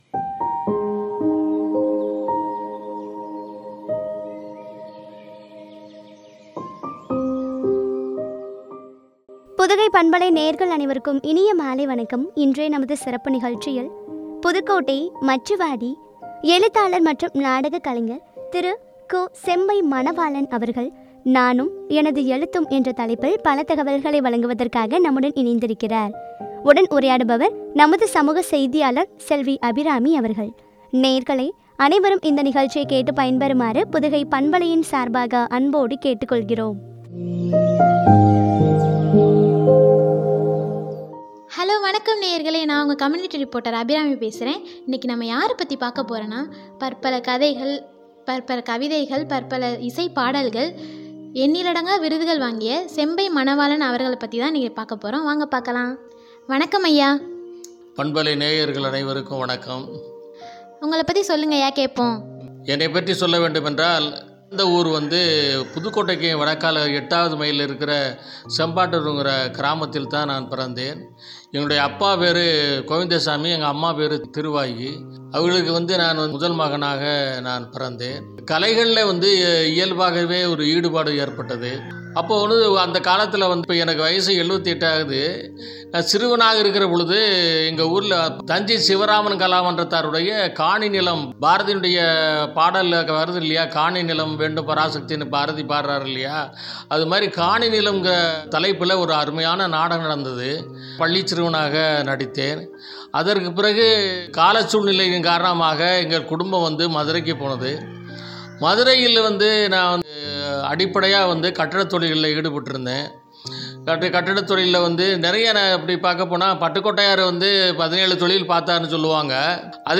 வழங்கும் உரையாடல்.